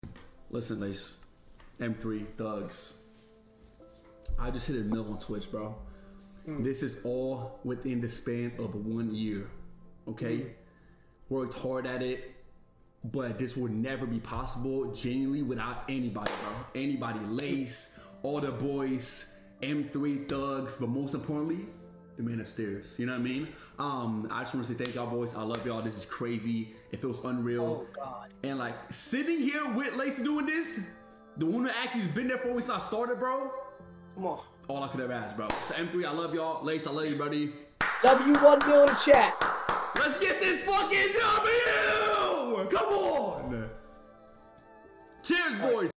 emotional speech